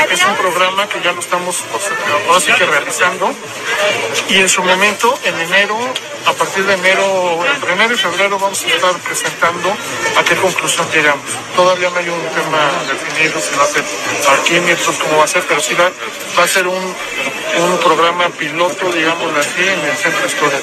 En entrevista, el funcionario municipal abundó que el programa de Estacionamiento Rotativo está en manos de la encargada de Despacho de la Secretaría de Movilidad para que cuando se tenga listo se efectúe la presentación.